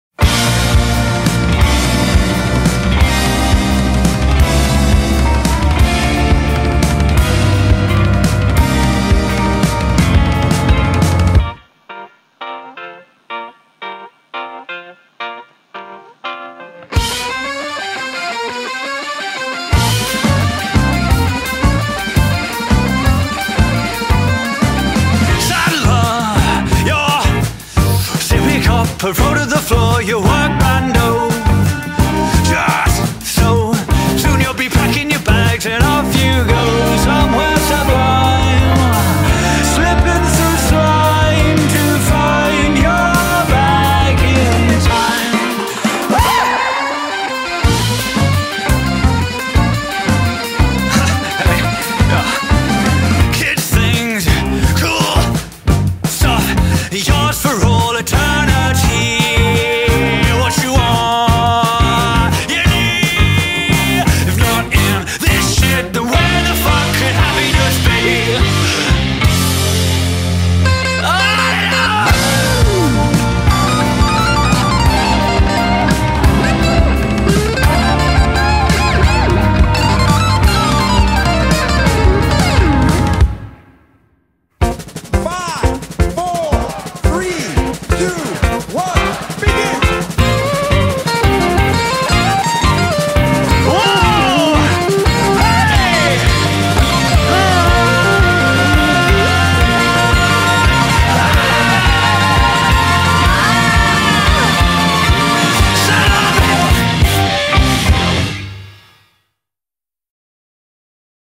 BPM172-172
Audio QualityMusic Cut